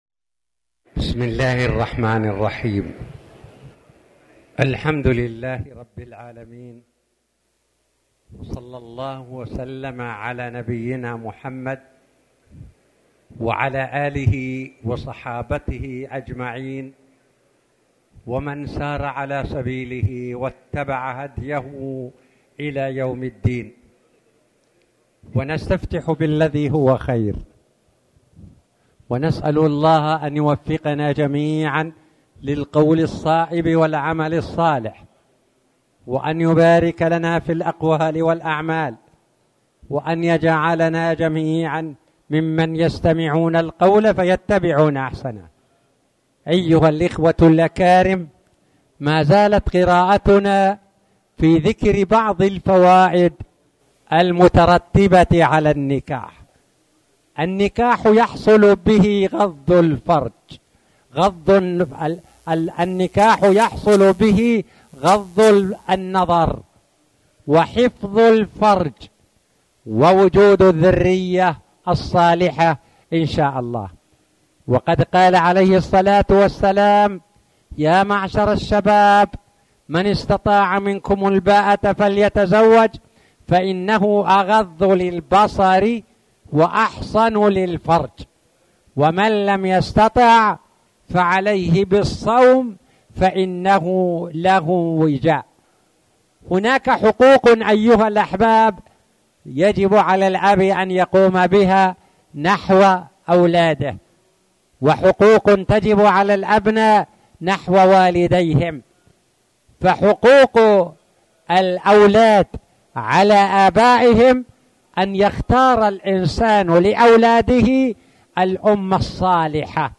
تاريخ النشر ٢٢ جمادى الأولى ١٤٣٨ هـ المكان: المسجد الحرام الشيخ